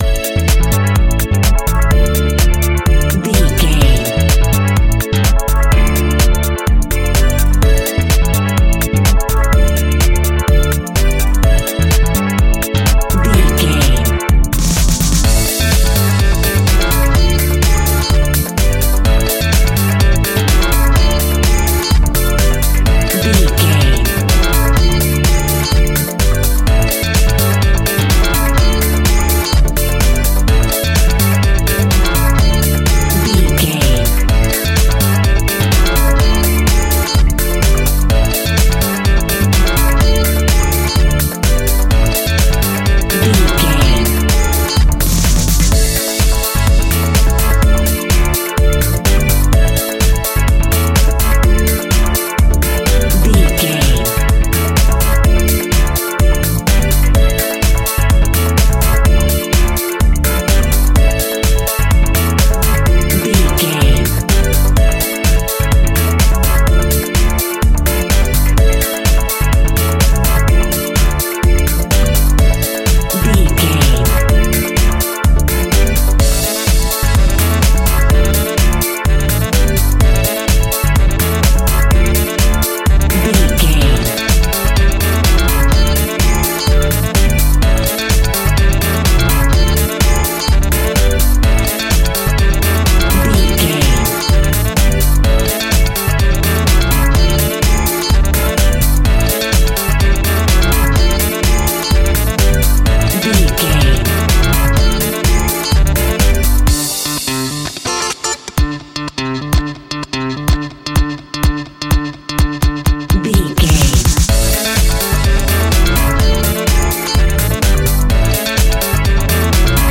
Aeolian/Minor
D
groovy
futuristic
hypnotic
uplifting
electric guitar
drum machine
synthesiser
bass guitar
horns
funky house
disco house
electronic funk
energetic
upbeat
electric piano
clavinet